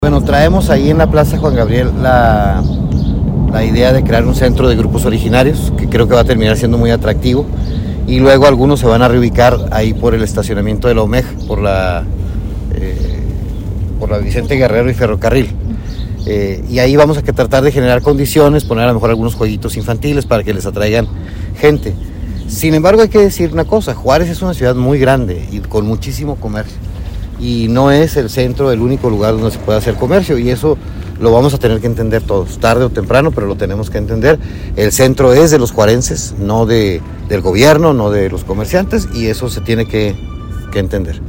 El alcalde de Ciudad Juárez, Cruz Pérez Cuellar, señaló que la Plaza Juan Gabriel será a corto plazo un punto atractivo, donde los vendedores ambulantes podrán comercializar sus productos.